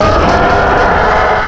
cry_not_rampardos.aif